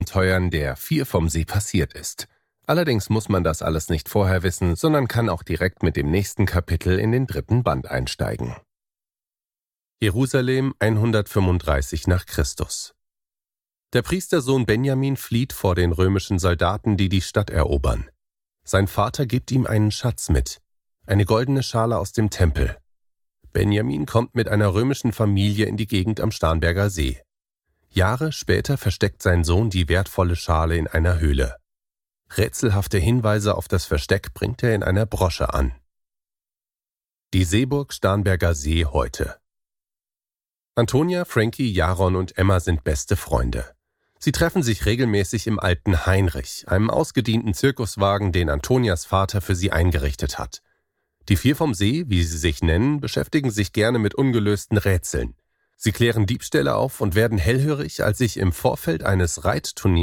(MP3-Hörbuch - Download)
Hörbücher für Kinder/Jugendliche